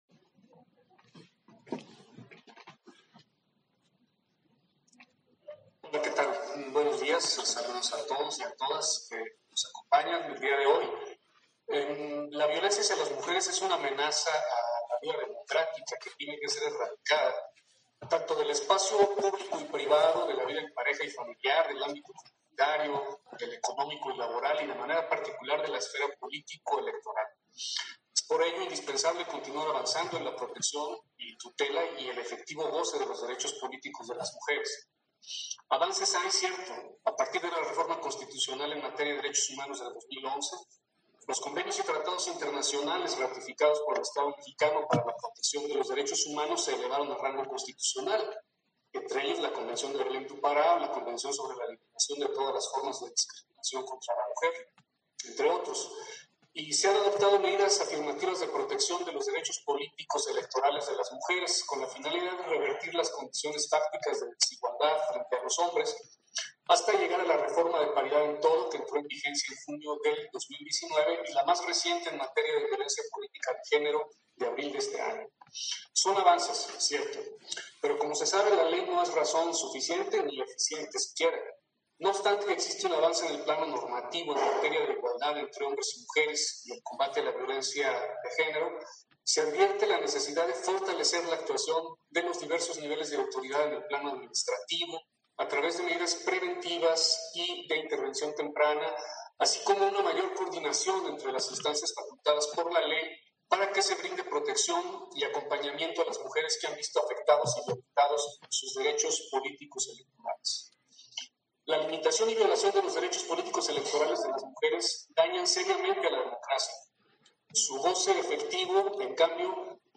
Intervención de Martín Faz, en el lanzamiento de los conversatorios: Desafíos para el cumplimiento de la paridad de género y el combate a la violencia política contra las mujeres ante los procesos electorales